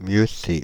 Mieuxcé (French pronunciation: [mjøse]
Fr-Paris--Mieuxcé.ogg.mp3